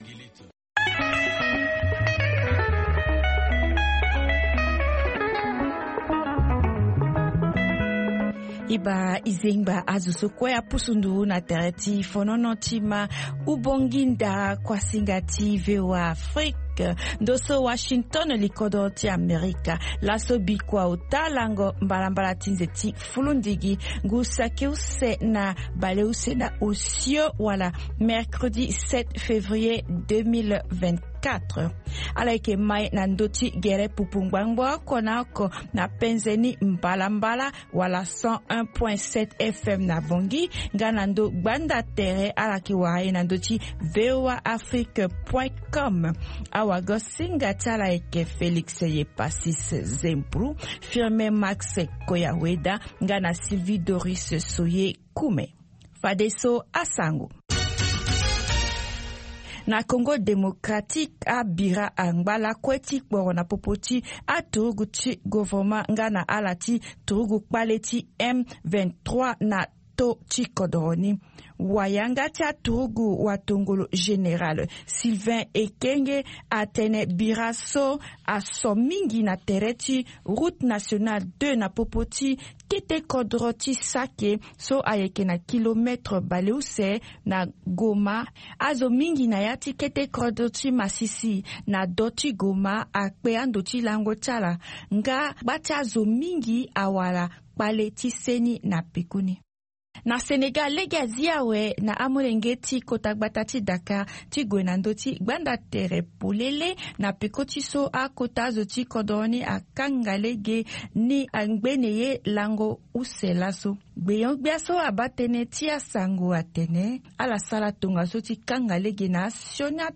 Zia i Tene, est un programme en sango comportant plusieurs rubriques sur l'actualité nationale et internationale, des interviews portant sur une analyse et des réactions sur des sujets divers ainsi qu'un volet consacré aux artistes.